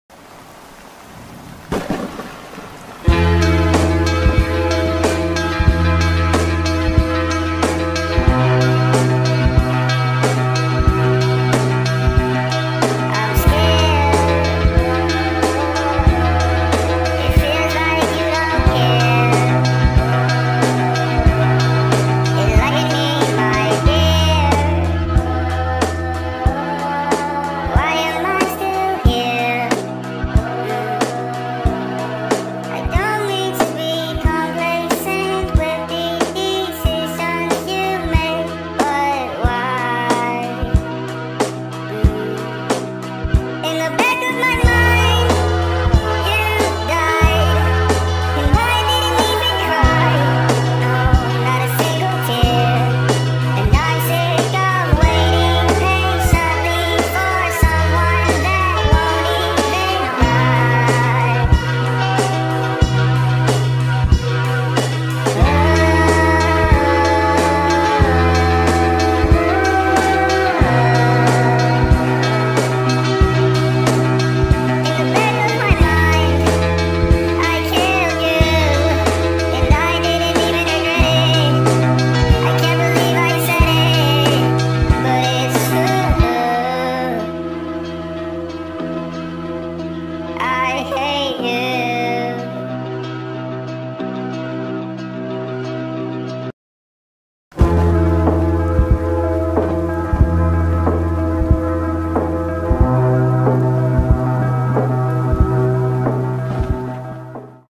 با ریتمی تند ، این اهنگ یک آهنگ احساسی و غمگین است
غمگین